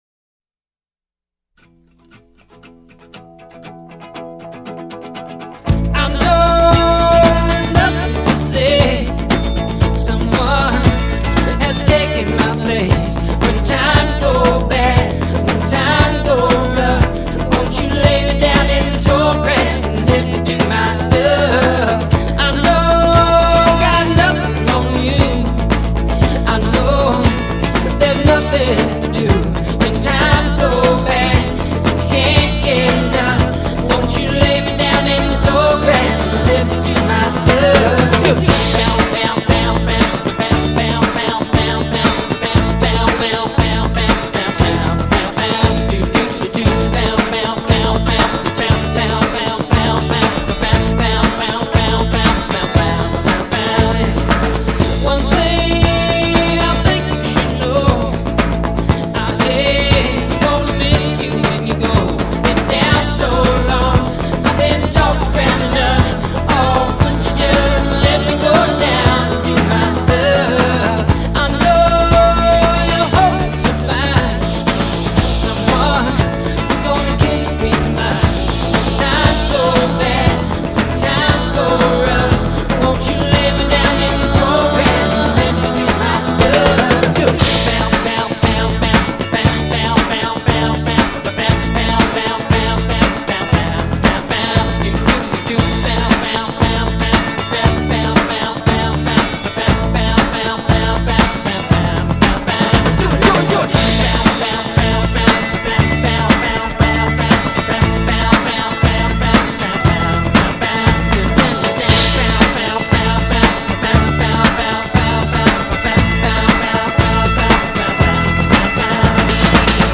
Pop Rock, Folk Rock